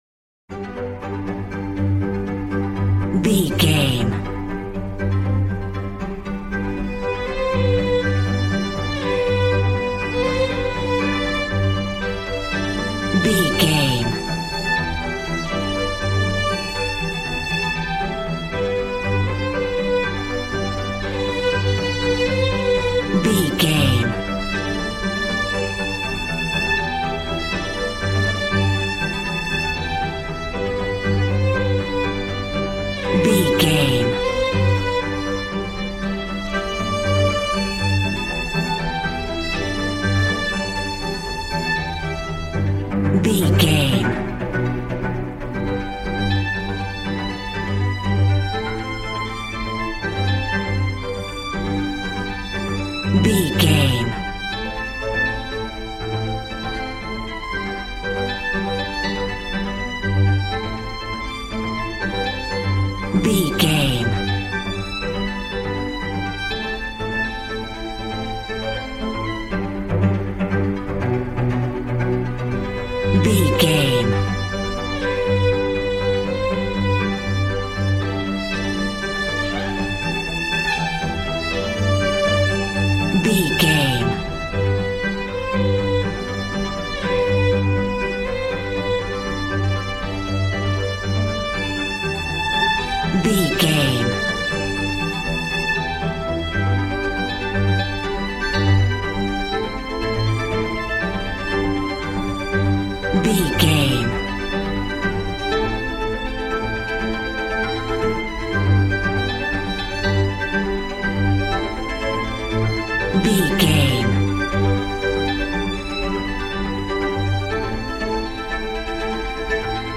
Modern film strings for romantic love themes.
Regal and romantic, a classy piece of classical music.
Ionian/Major
regal
cello
violin
brass